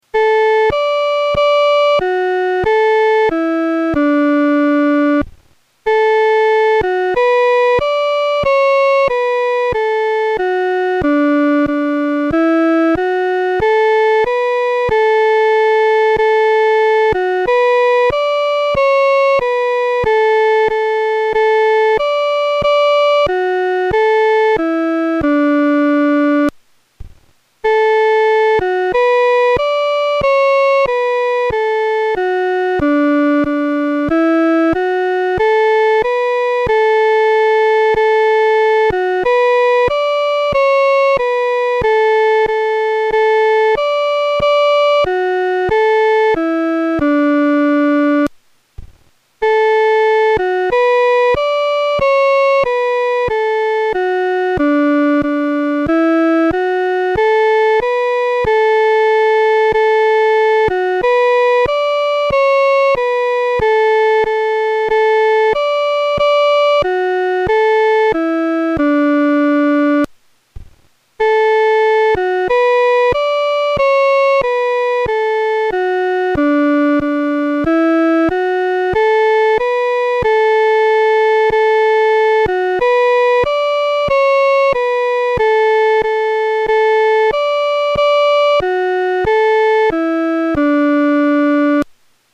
女高伴奏